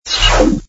map_tabs_close.wav